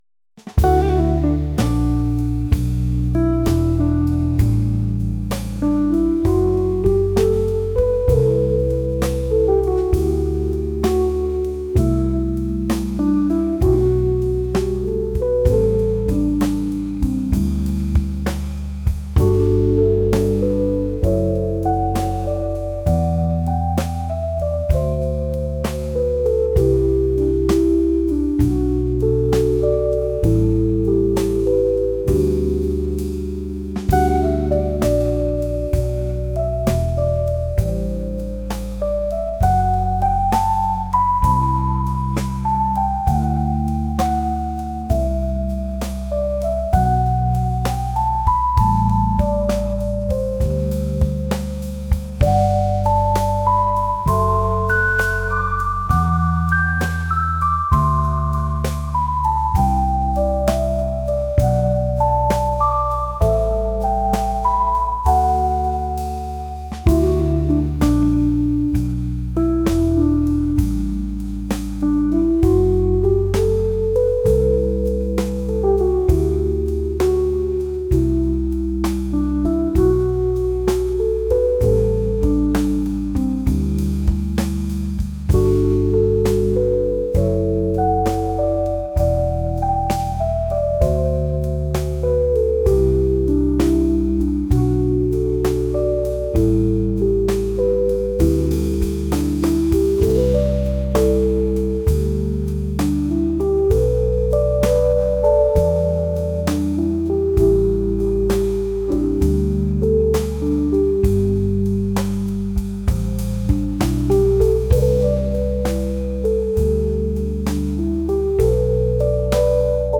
jazz | soul & rnb | ambient